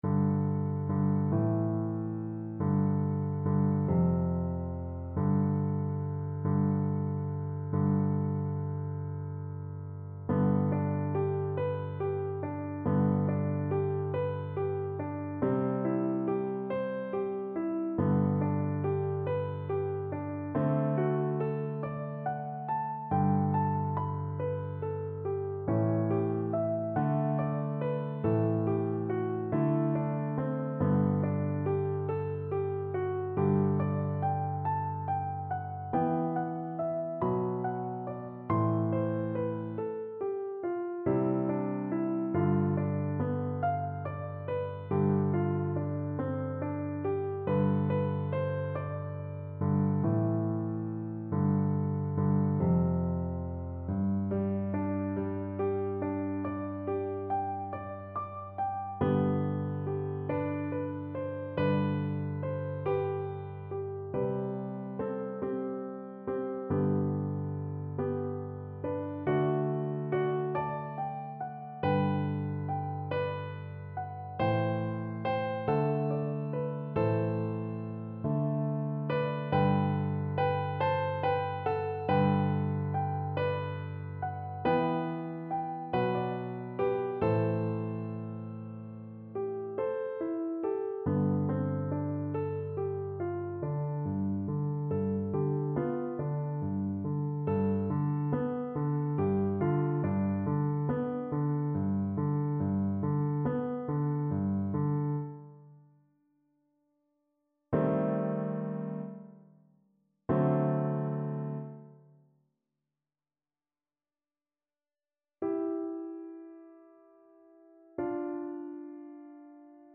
Play (or use space bar on your keyboard) Pause Music Playalong - Piano Accompaniment Playalong Band Accompaniment not yet available transpose reset tempo print settings full screen
Flute
G major (Sounding Pitch) (View more G major Music for Flute )
6/8 (View more 6/8 Music)
Andante .=c.60
Traditional (View more Traditional Flute Music)